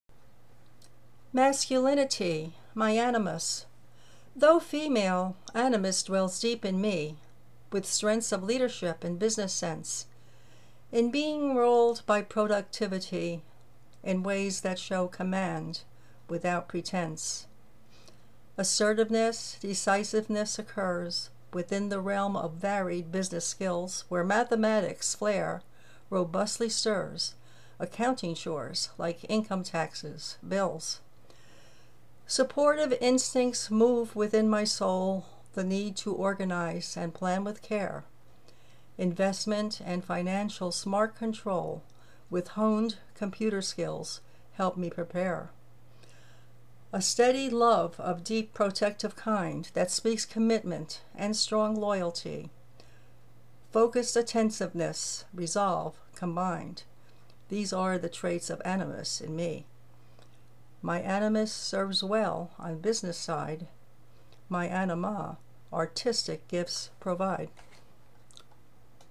Loved the assertiveness in your voice!